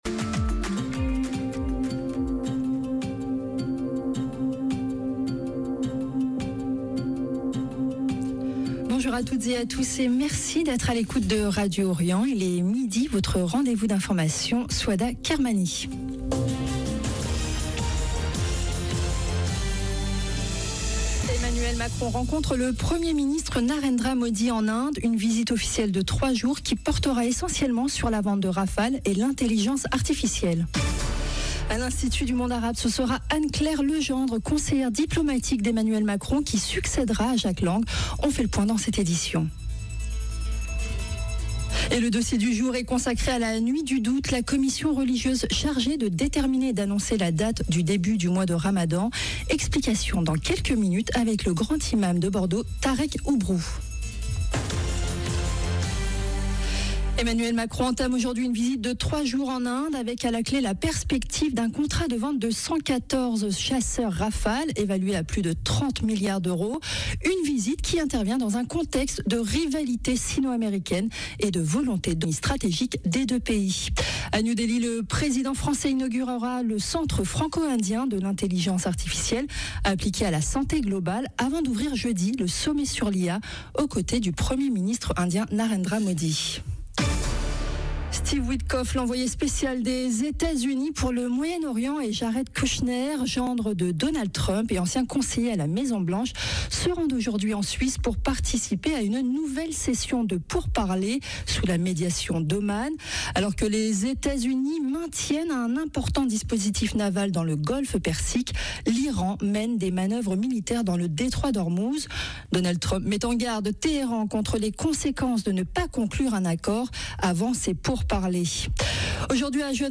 Radio Orient Journal de midi